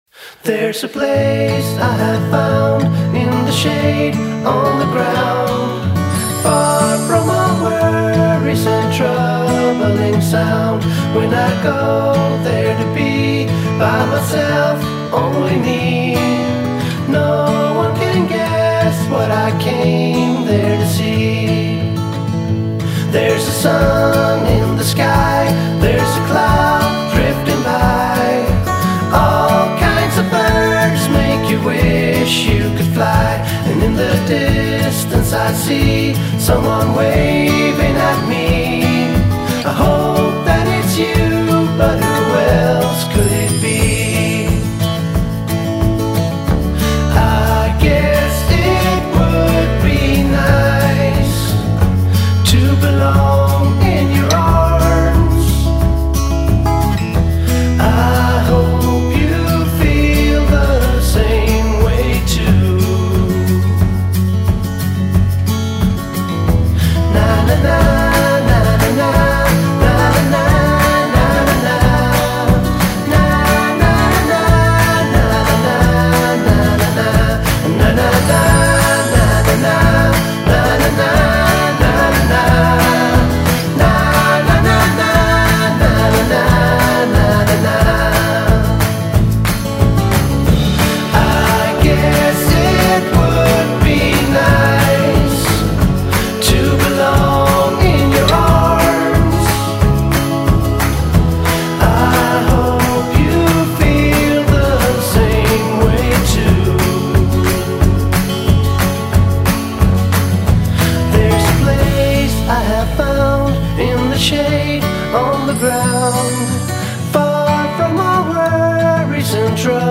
※不正使用防止の為効果音が等間隔に挿入されております。
著作権フリー楽曲（音源の準備必要なし・料金無料）
【シットリ系：男性ボーカル】カントリー調の曲でエンドロールにも合いそう